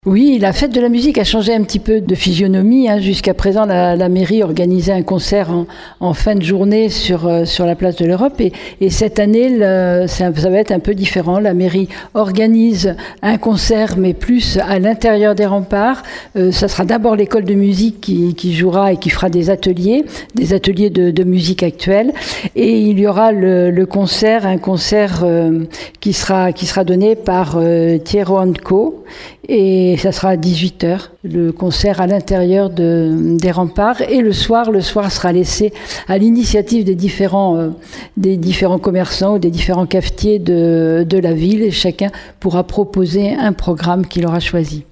Et en soirée, la municipalité donne cette année carte blanche aux commerçants. Écoutez Catherine Desprez, maire de Surgères :